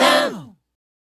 Vox Stab-D.wav